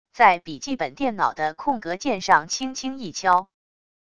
在笔记本电脑的空格键上轻轻一敲wav音频